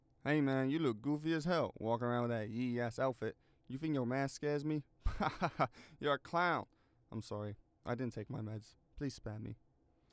Voice Lines / Street barklines
hey man you look goofy as hell touthink your mask.wav